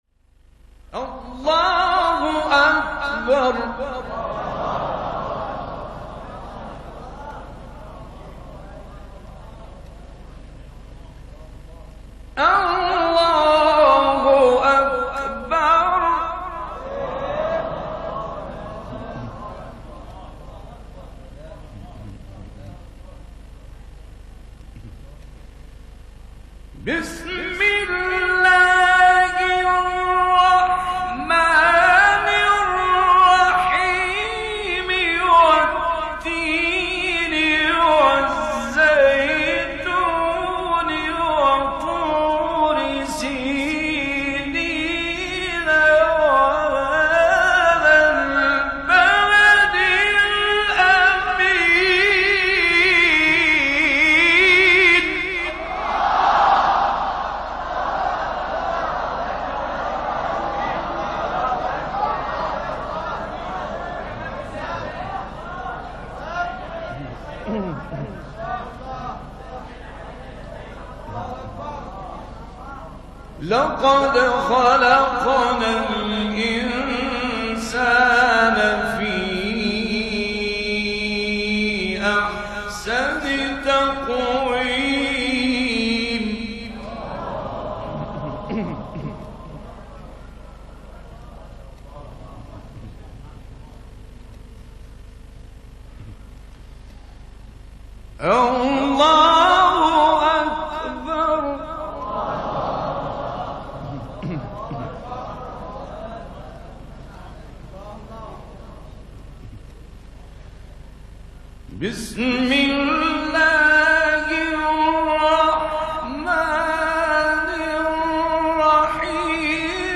تلاوت زیبا سوره تین استاد راغب مصطفی غلوش | نغمات قرآن
سوره : تین آیه: تمام سوره استاد : راغب مصطفی غلوش مقام : ترکیب بیات و رست قبلی بعدی